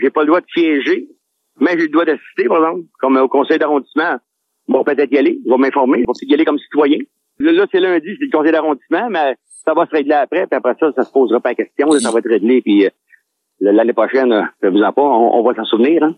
C’est ce que le conseiller du district de Laflèche a déclaré sur nos ondes ce vendredi, au lendemain de l’annonce qu’il ne pouvait plus siéger avec les autres élus.